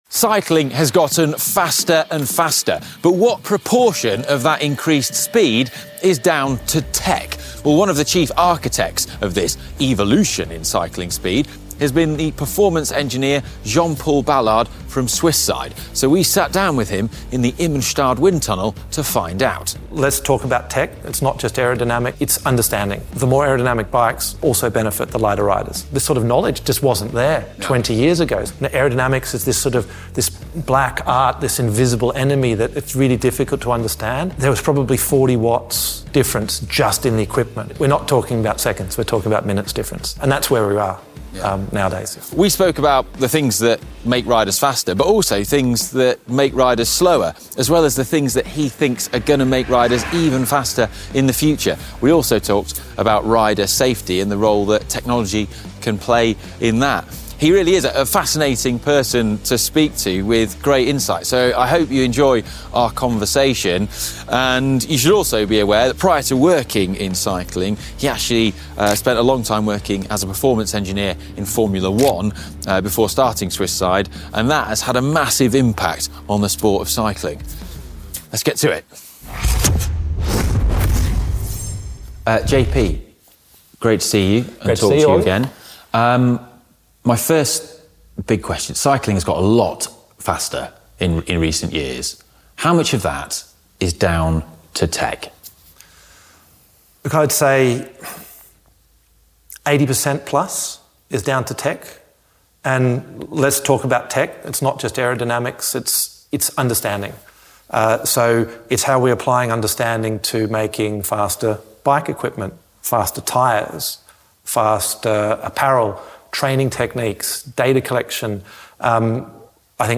and we sat down with him at the Immenstadt wind tunnel in Germany to discuss all the things that make riders faster and the things that don't. As well as the things that will make us faster in the future! He’s a fascinating person to speak to, so we hope you enjoy our conversation!